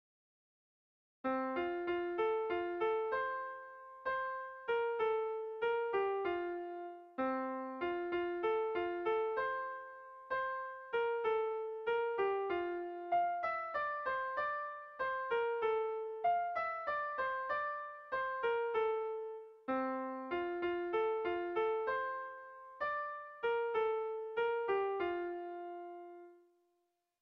Bertso melodies - View details   To know more about this section
Zortziko txikia (hg) / Lau puntuko txikia (ip)
AABA